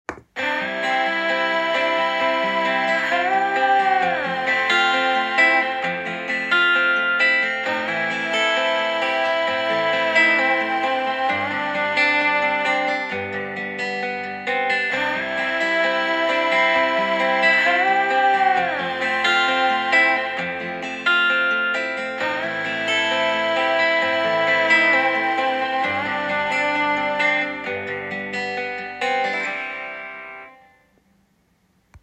Below are two comparative recordings of music and speech.
MUSIC
musik-luftkabel-sferics-smart.m4a